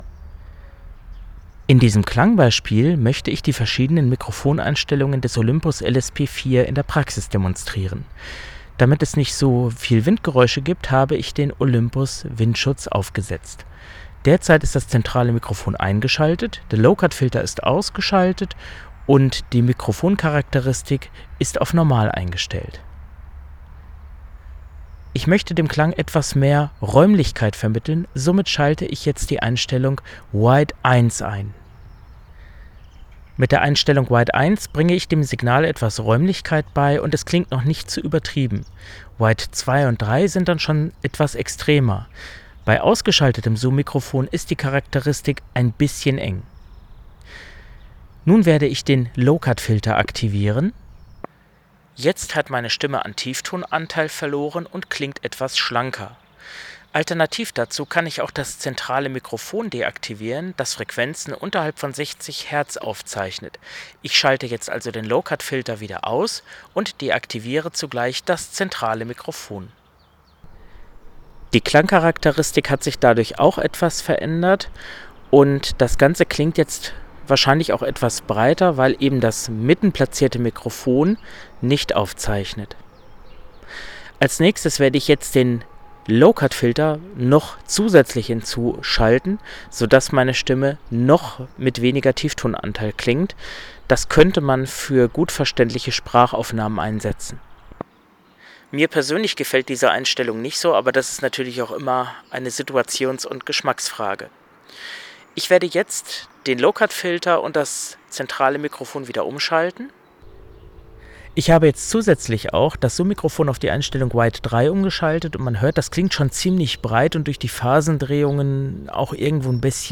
olympus_ls_p4_vergleich_der_automatischen_aussteuerungen.mp3